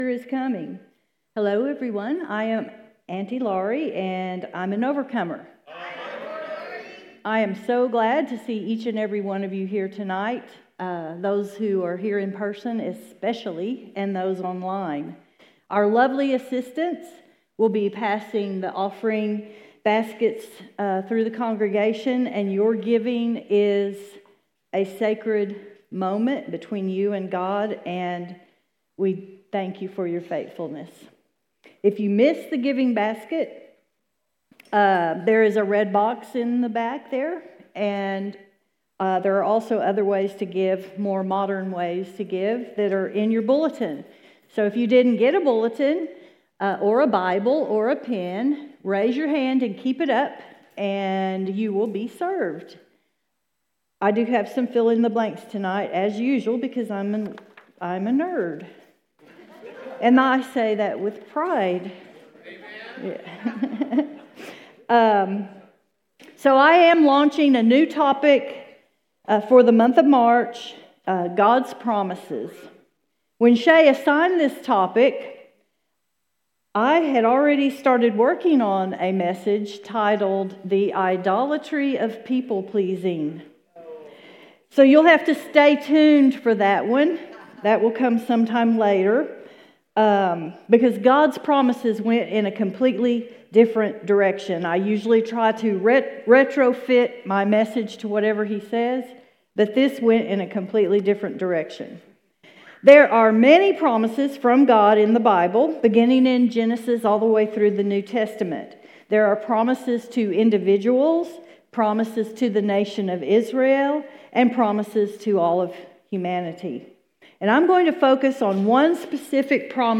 Sermons | Serenity Church